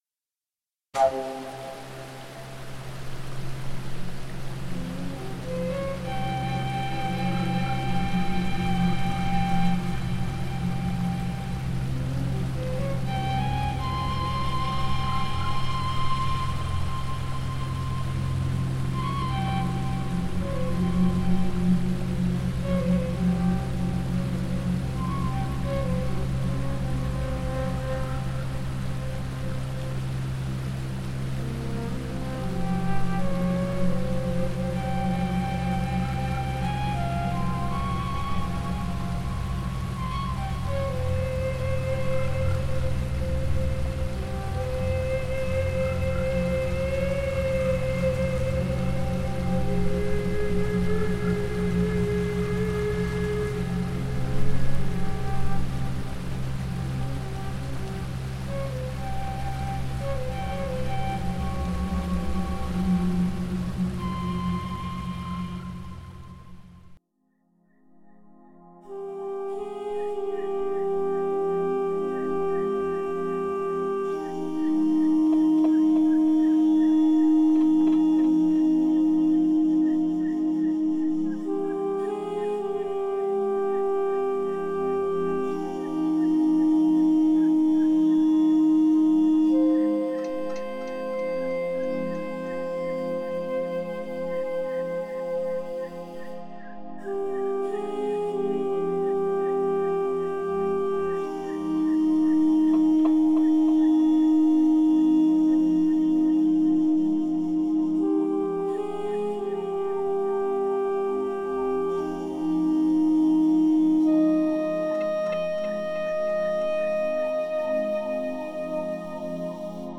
Atmospheric Demo Reel
Atmospheric_Reel.mp3